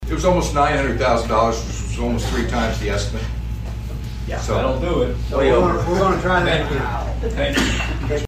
County Clerk Kevin Engler says the first bid was rejected as it went way over the county's estimated cost.